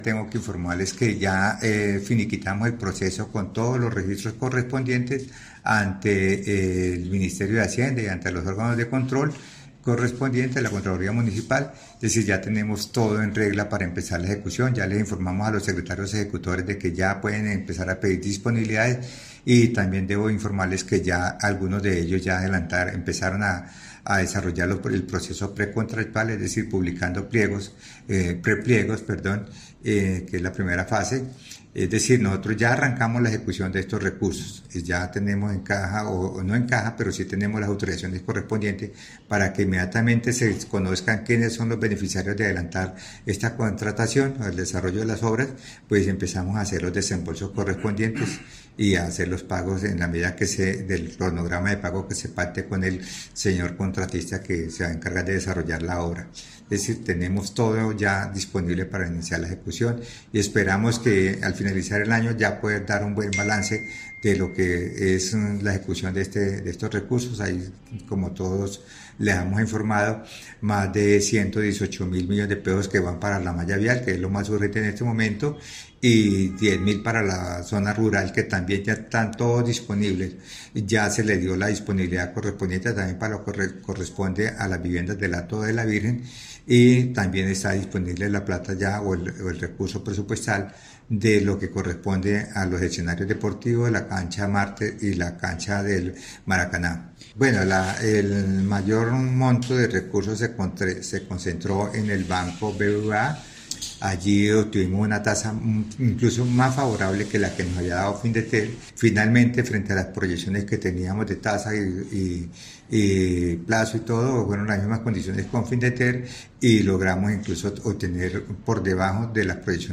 Escuche las declaraciones de Ángel María Gómez – secretaria de Hacienda del municipio: